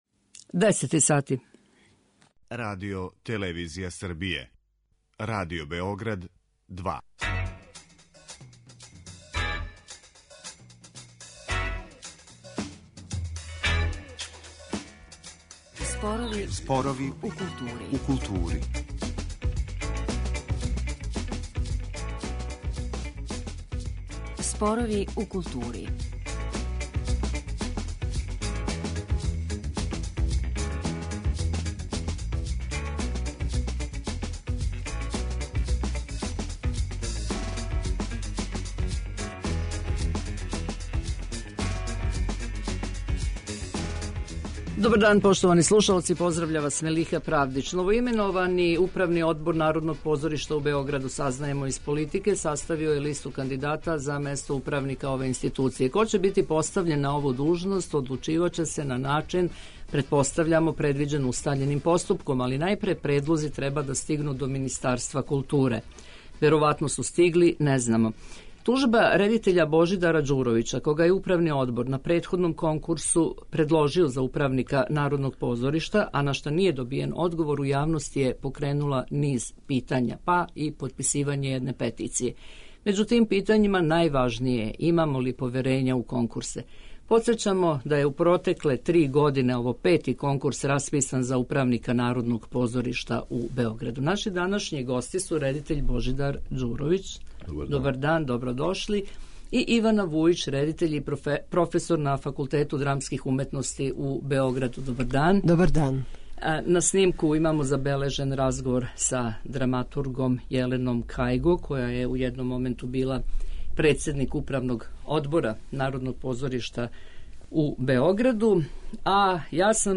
а на снимку имамо забележен разговор